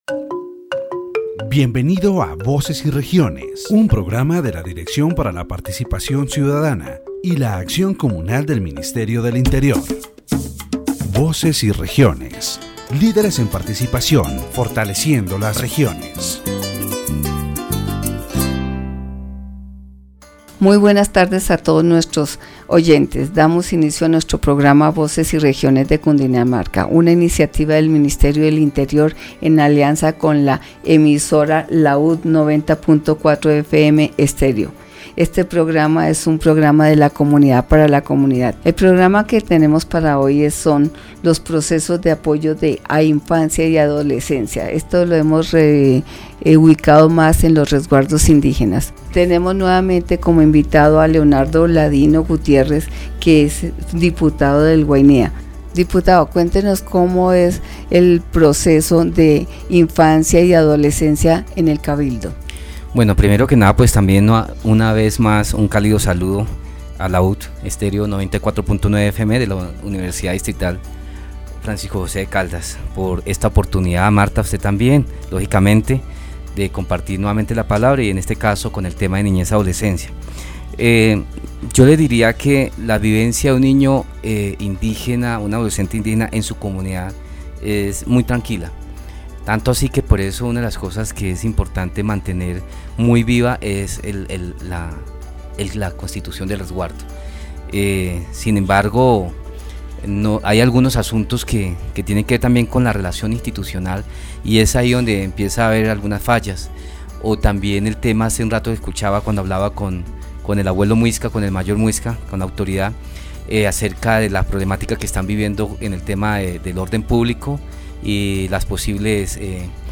The radio program "Voices and Regions" of the Directorate for Citizen Participation and Community Action of the Ministry of the Interior focuses on the processes of support for children and adolescents in indigenous reservations. The guests, Leonardo Ladino Gutiérrez, deputy of Guainía, and José Alberto Duitama, governor of Estocán Cipa, discuss the living conditions of indigenous children and adolescents, the risks they face, and the protection and support measures that are implemented in their communities. Topics such as traditional medicine, education, the presence of armed groups, prostitution and the need to strengthen indigenous leadership to improve the quality of life of these communities are addressed.